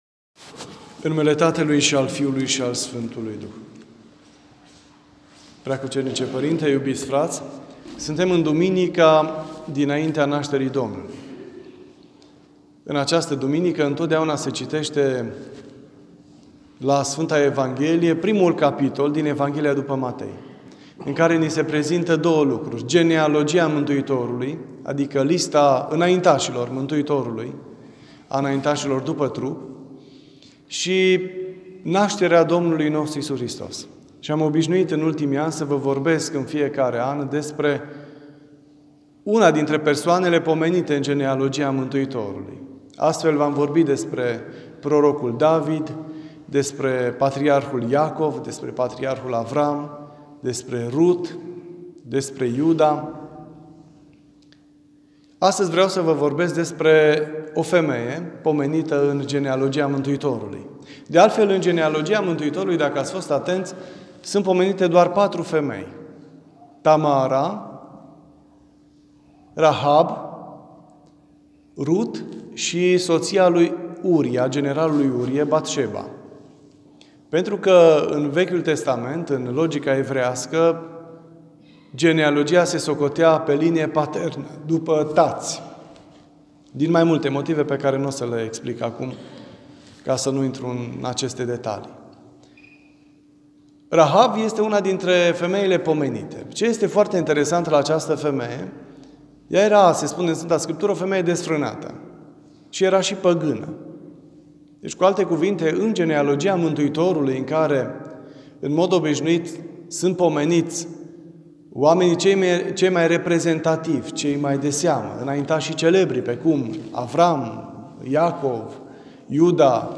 Cuvânt la Duminica dinaintea Nașterii Domnului.